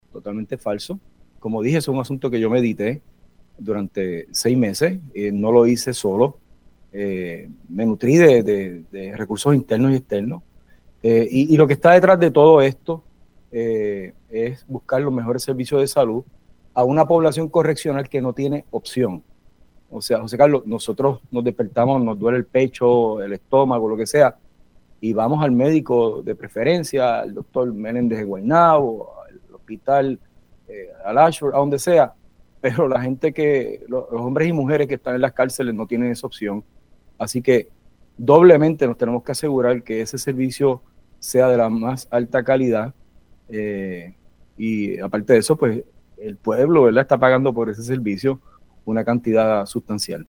El secretario de Corrección, Francisco Quiñones aseguró en Pega’os en la Mañana que hizo lo correcto al informar sobre la cancelación del contrato de servicios médicos de Physician Correctional.